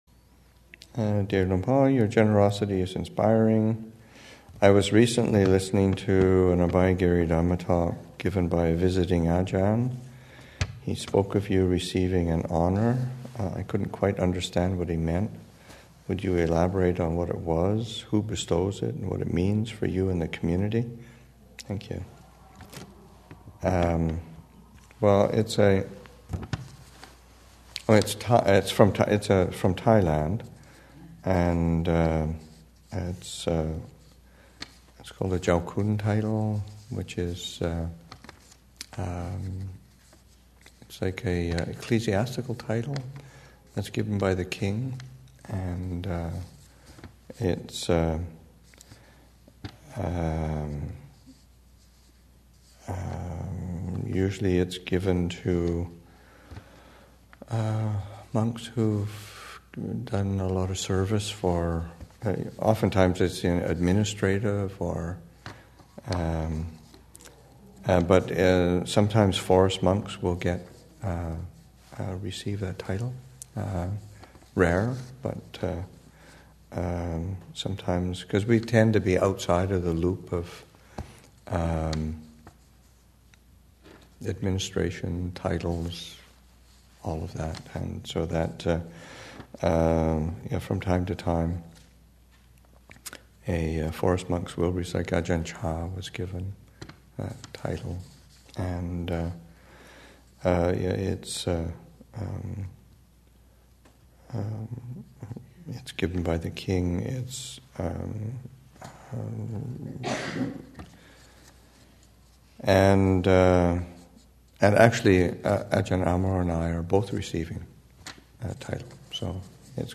2015 Thanksgiving Monastic Retreat, Session 7 – Nov. 27, 2015